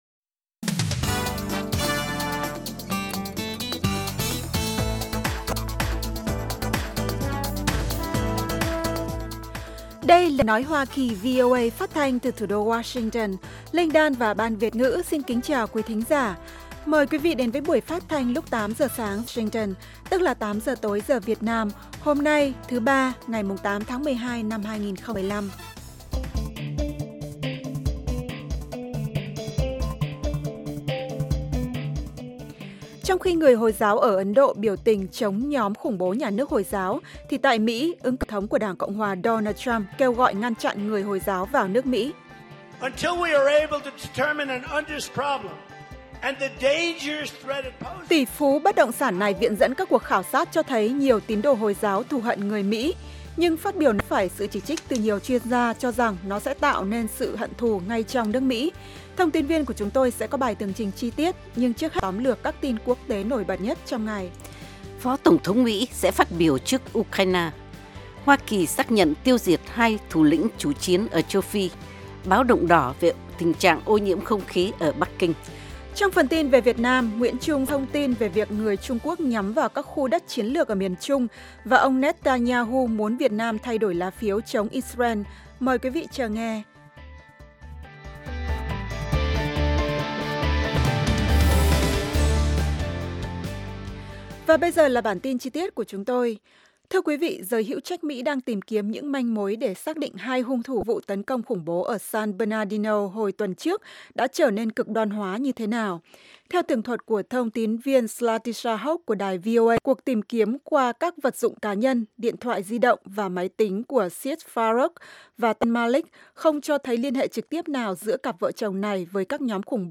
Tin tức luôn cập nhật, thời sự quốc tế, và các chuyên mục đặc biệt về Việt Nam và thế giới. Các bài phỏng vấn, tường trình của các phóng viên VOA về các vấn đề liên quan đến Việt Nam và quốc tế.